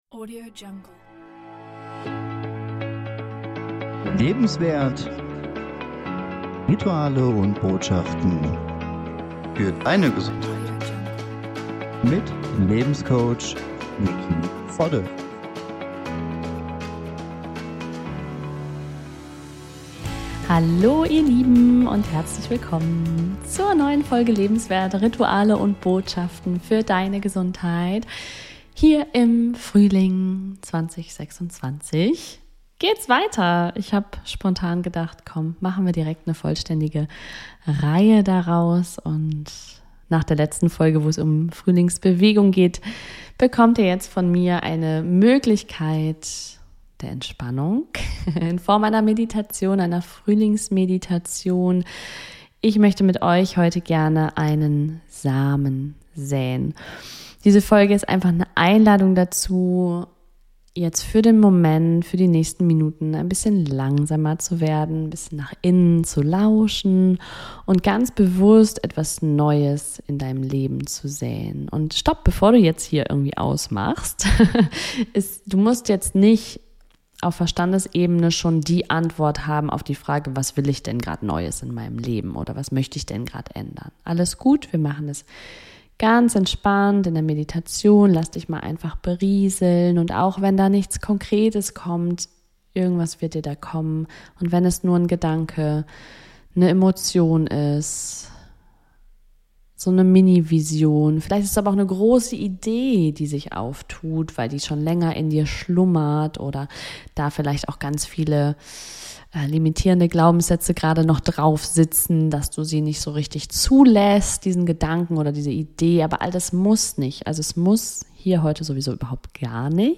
In dieser geführten Meditation darfst du zur Ruhe kommen, dich mit der Energie des Frühlings verbinden und eine klare Intention setzen – wie einen Samen, den du in die Erde deines Lebens pflanzt.